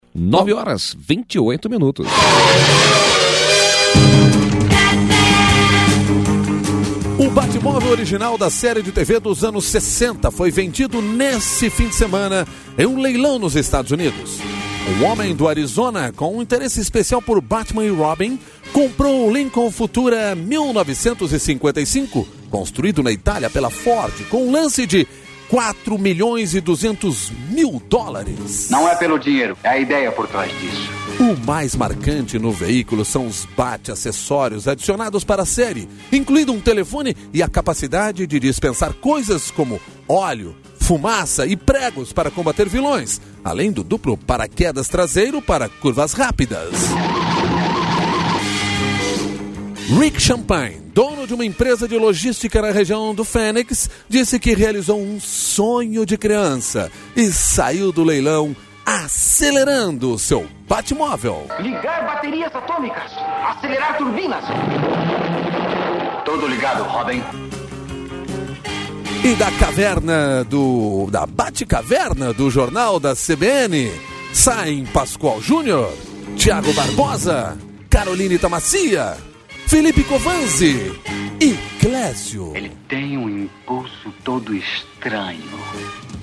A charge do JCBN: o carro do Batman